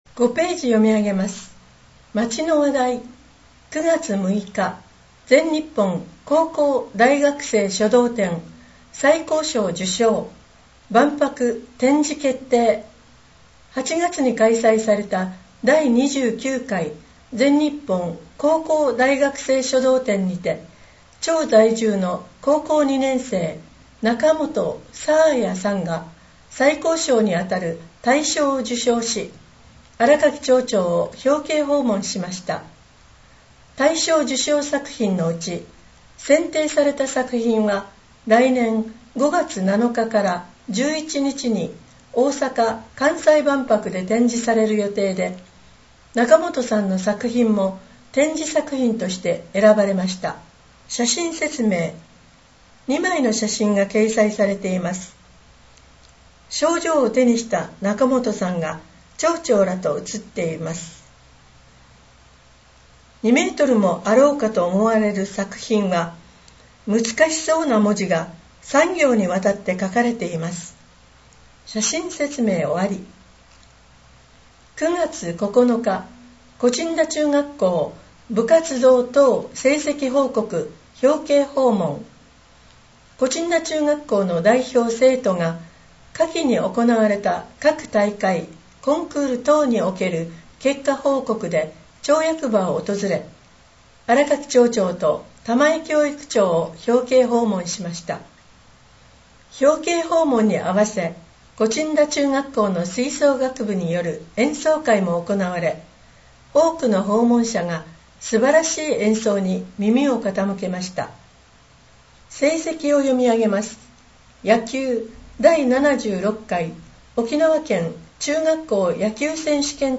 声の「広報やえせ」　令和6年10月号226号